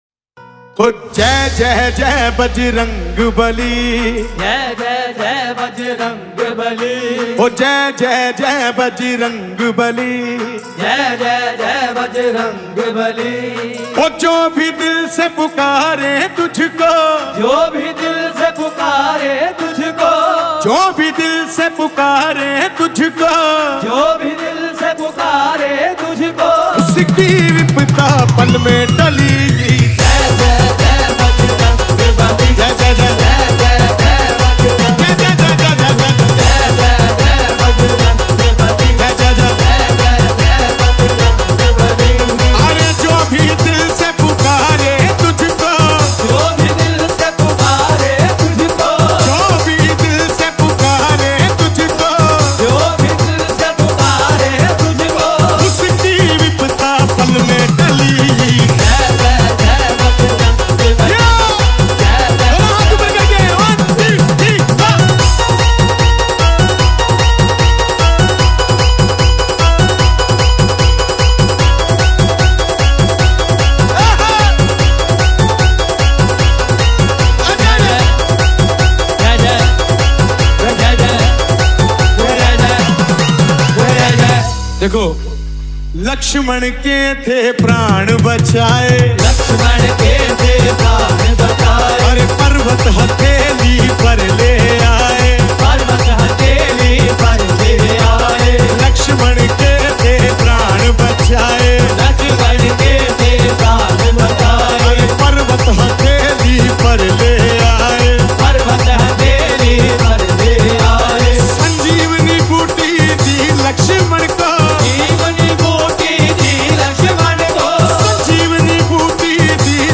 • Genre: Devotional DJ Remix / EDM Mix
• Heavy bass and energetic drops